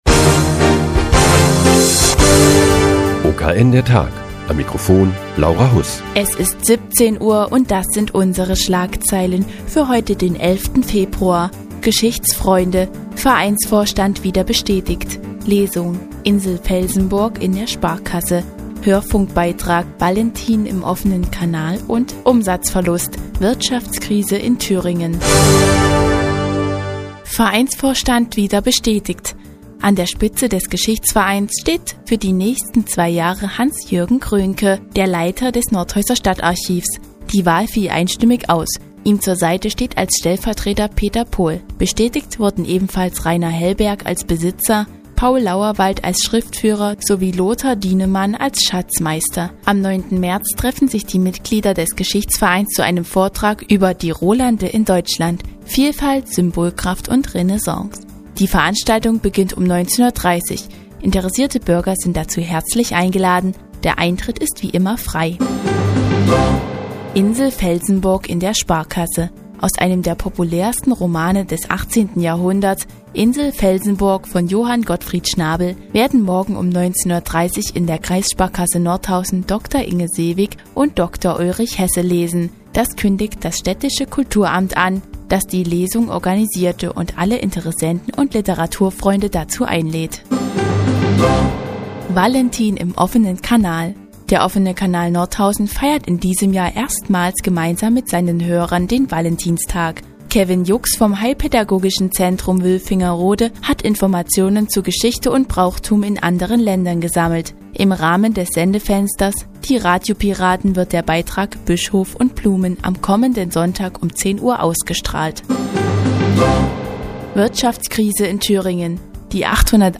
Die tägliche Nachrichtensendung des OKN ist nun auch in der nnz zu hören. Heute geht es um die morgige Lesung in der Kreissparkasse Nordhausen und den Umsatzverlust in Thüringen.